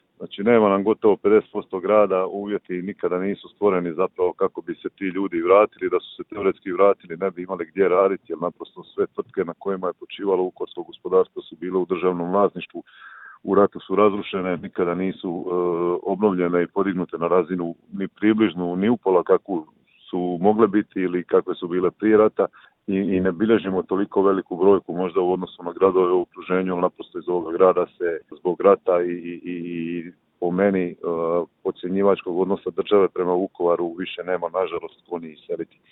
ZAGREB - Uoči obilježavanja vukovarske tragedije kratko smo razgovarali s gradonačelnikom Vukovara Ivanom Penavom.